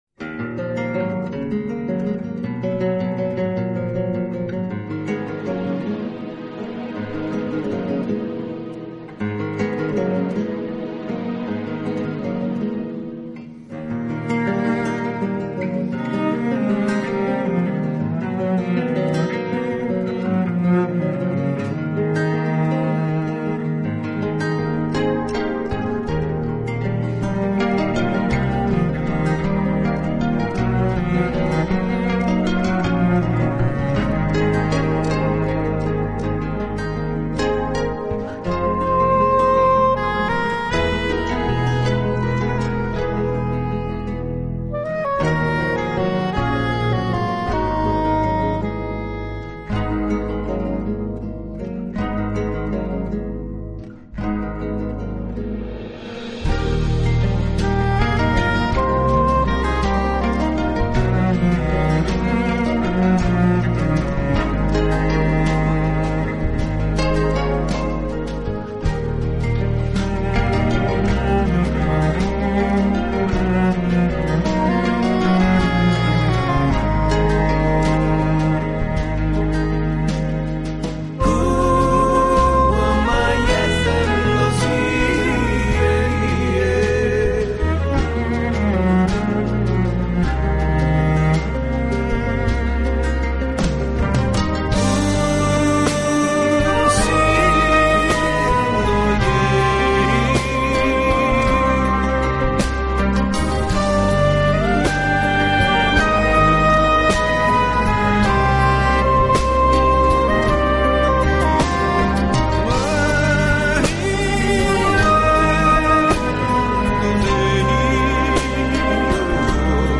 【竖琴专辑】
带给我们的欢乐，他的作品悠闲富有情趣，没有那股纵欲过度、自我毁灭的倾向。